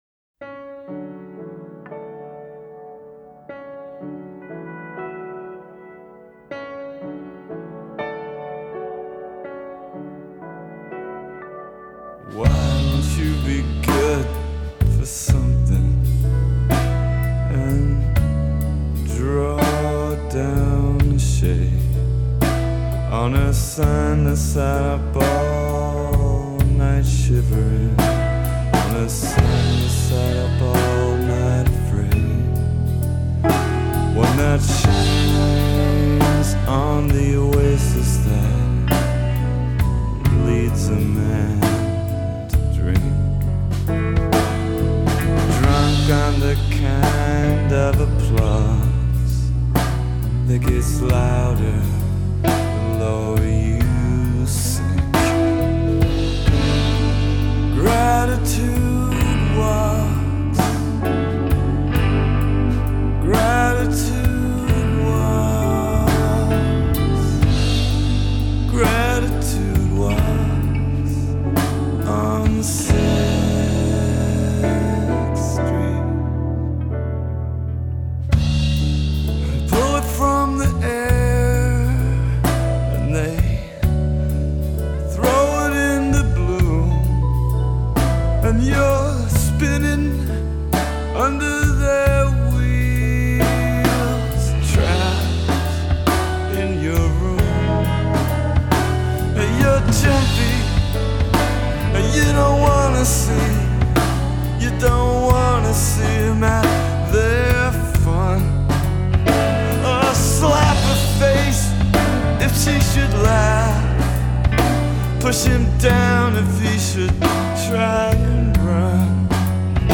Open, vulnerable, haunting and beautiful.